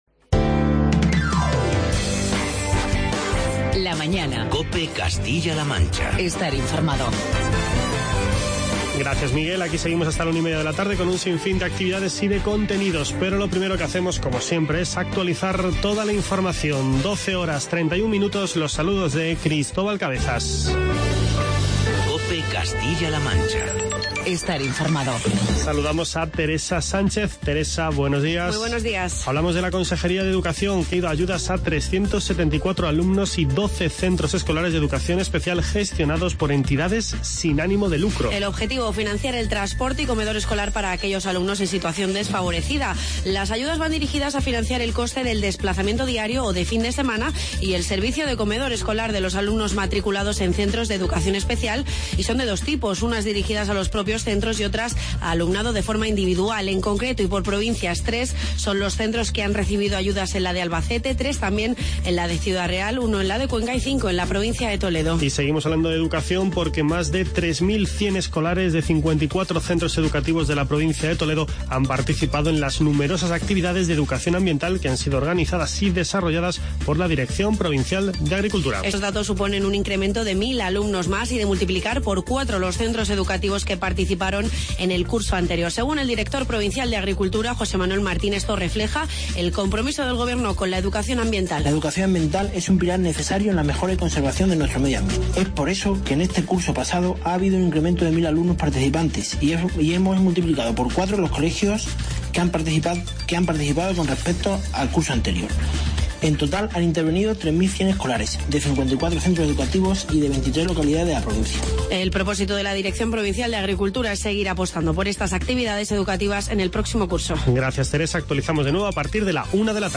Charlamos con Teodoro Santos, alcalde de Fuente el Fresno, y con Gabriel López-Colina, primer edil de Calera y Chozas.